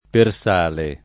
[ per S# le ]